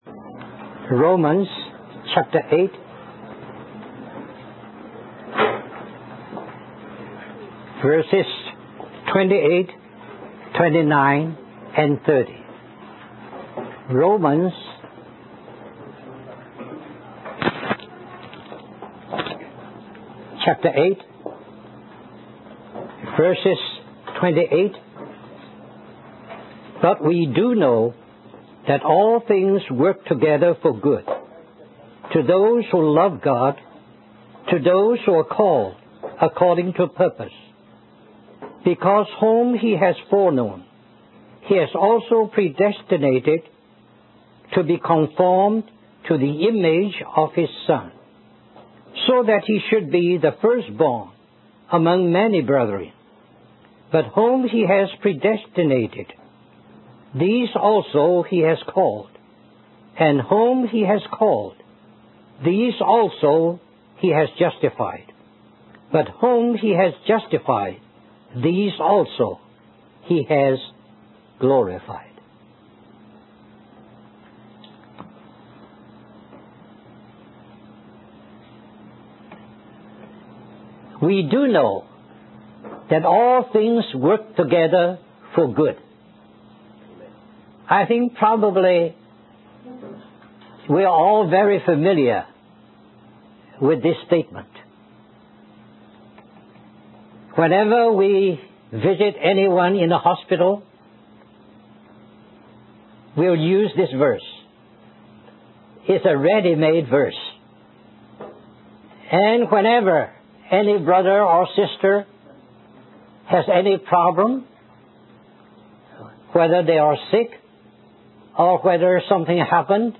In this sermon, the preacher emphasizes that God is the orchestrator of all things and brings them together for a definite purpose. The sermon references a story from Mark chapter 10 where a young man asks Jesus what he must do to inherit eternal life.